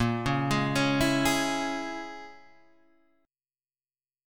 A#m7b5 chord